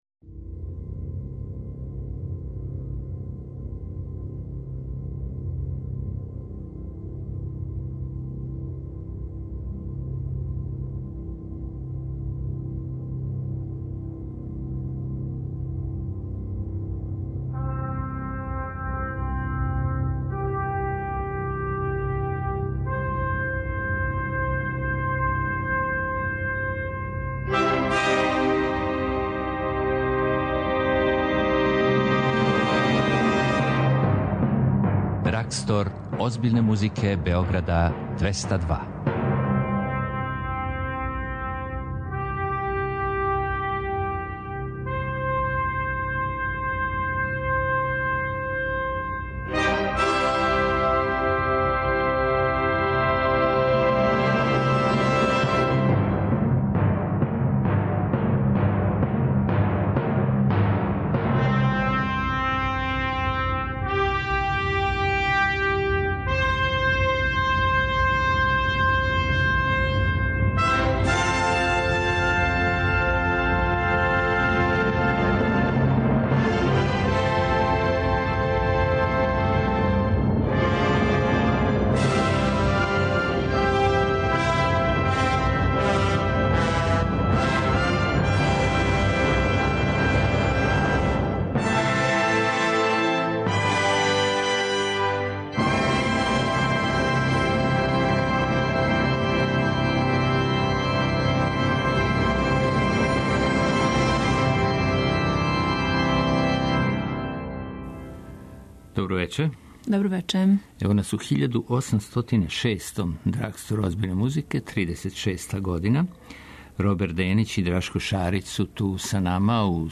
преузми : 57.00 MB Драгстор озбиљне музике Autor: Београд 202 Драгстор озбиљне музике Београда 202 већ 3 деценије промовише класичну музику, њене "хитове" и највеће ауторе, испуњава жеље слушалаца, директнo преноси и организује концерте.